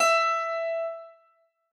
Harpsicord
e5.mp3